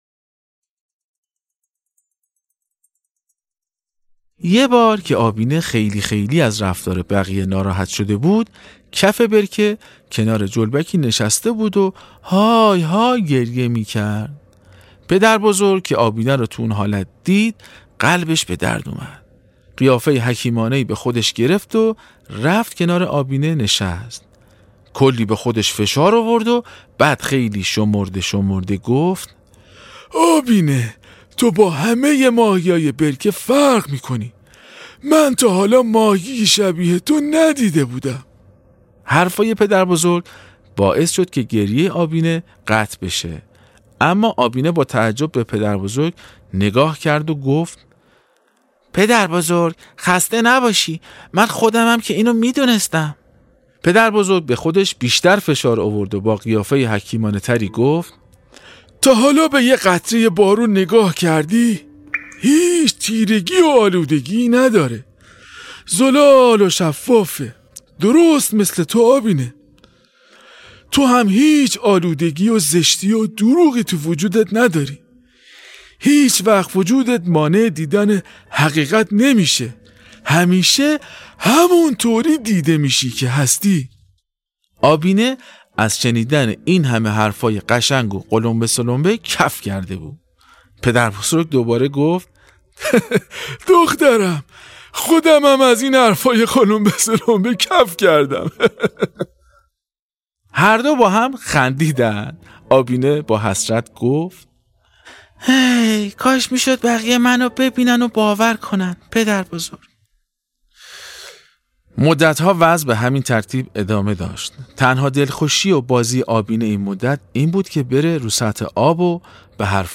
داستان ماهی ای که از یه جریان خیلی مهم برامون صحبت میکنه. باهم قسمت دوم از داستان صوتی آبینه رو بشنویم.